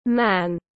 Người đàn ông tiếng anh gọi là man, phiên âm tiếng anh đọc là /mæn/.
Man /mæn/